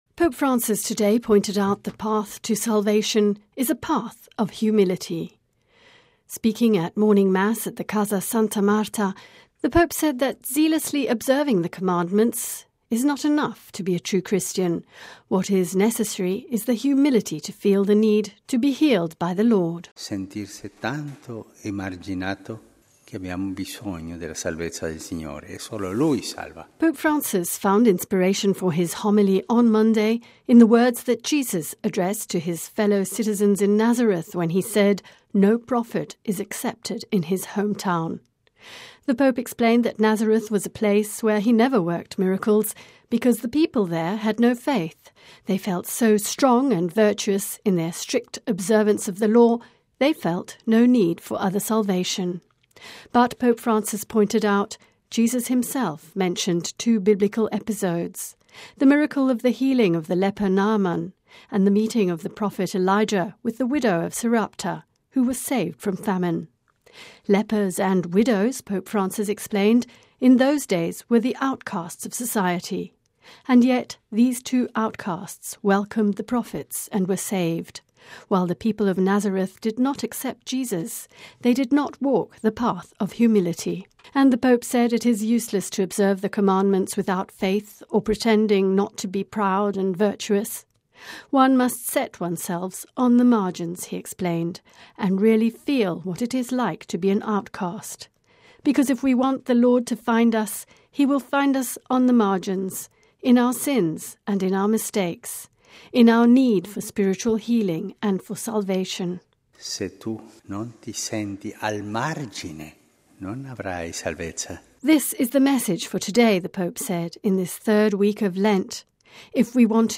(Vatican Radio) Our salvation is not just in observing the Commandments, but in the humility to always feel the need to be healed by God. This was the message voiced by Pope Francis during Mass on Monday morning at the Casa Santa Marta.